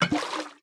sounds / item / bottle / fill2.ogg